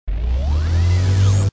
equipment_cart_start.wav